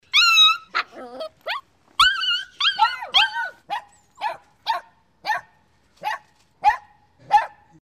Звуки скуления собаки
Щенок тихонько скулит